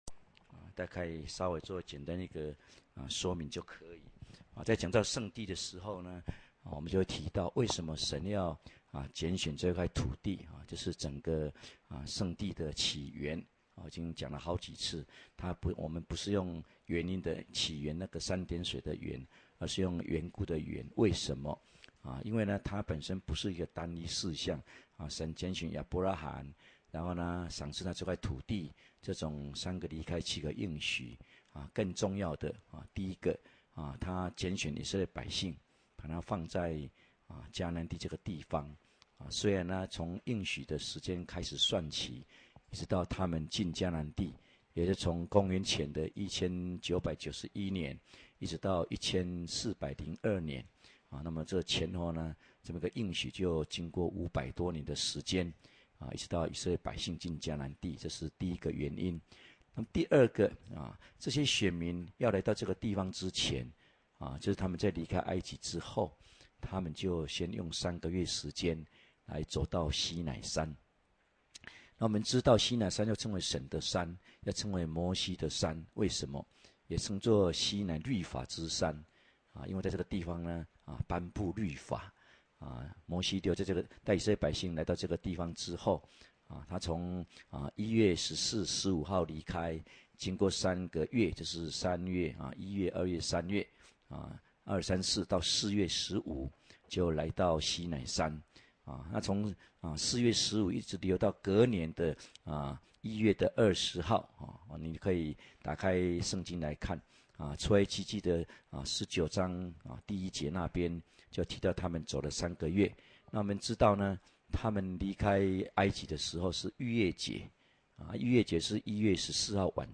認識聖地(五)-講道錄音